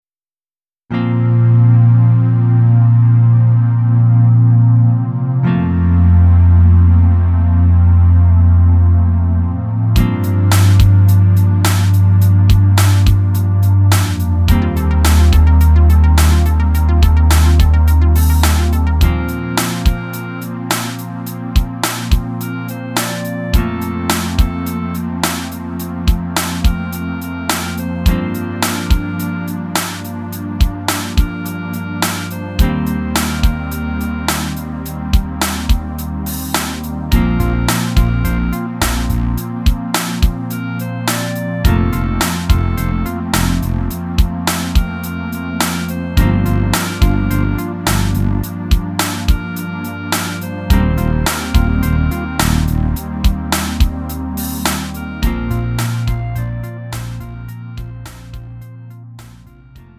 미리듣기
음정 -1키
장르 가요 구분 Lite MR